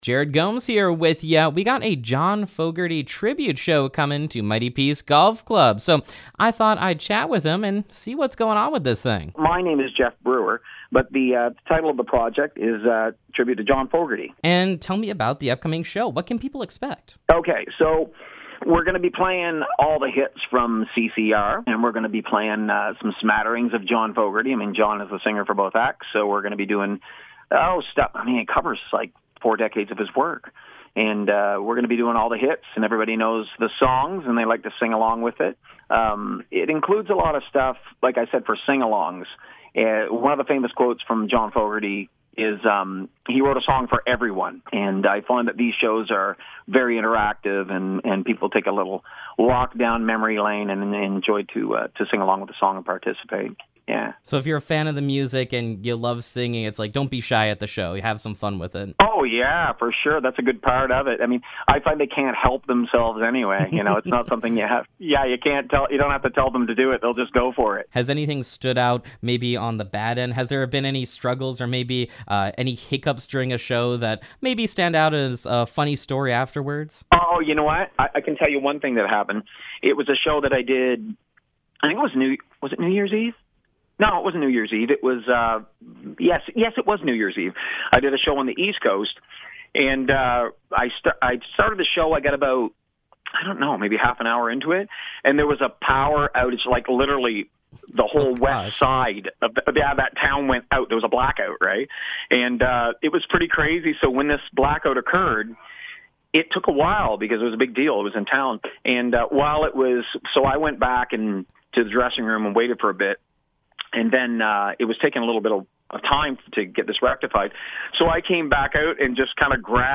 John Fogerty Tribute Show Interview
john-fogerty-interview-final.mp3